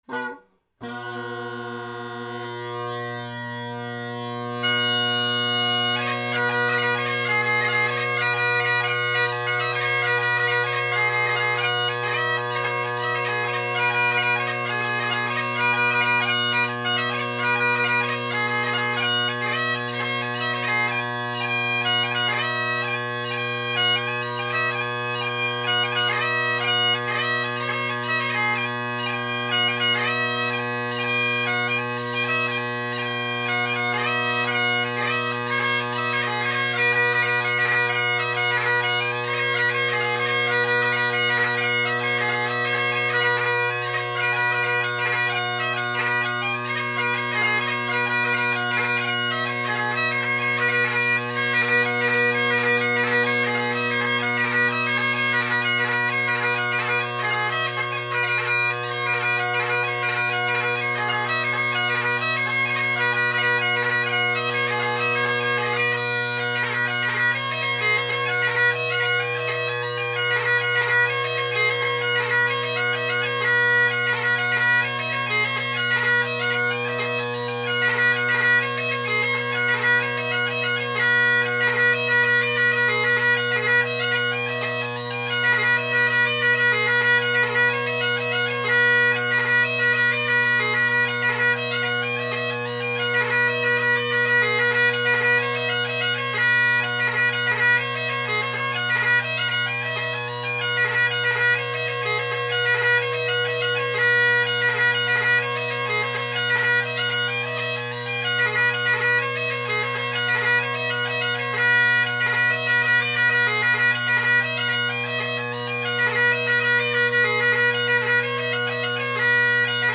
Great Highland Bagpipe Solo
All the pipes below are played with blackwood Colin Kyo chanters and either a Husk or Gilmour reed. First on is my D. MacPherson’s (Kinnaird tenors, Rocket bass, band chanter) with a couple jigs, the second composed by EJ Jones that I transcribed from his album “The Willow”.